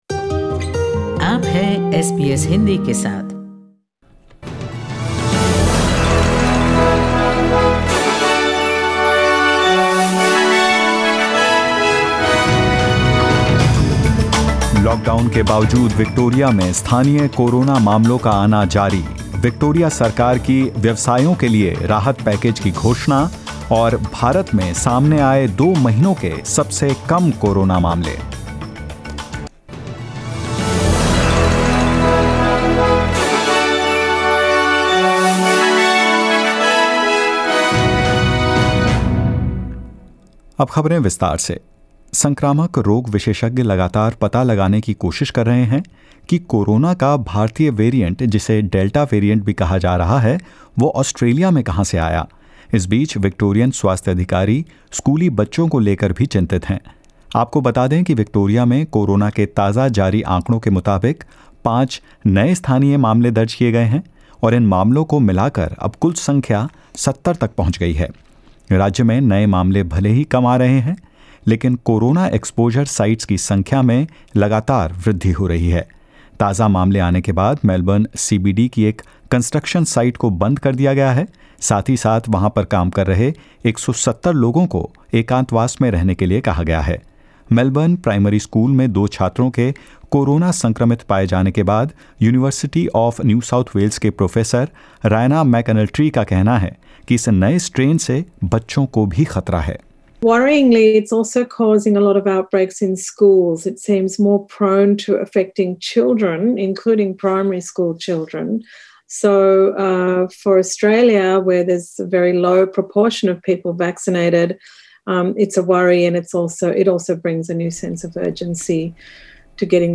In this latest SBS Hindi News bulletin of Australia and India: Victorian health authorities continue the search for the origin of a highly infectious COVID-19 variant in the state; India registers the lowest Covid-19 cases in two months and more.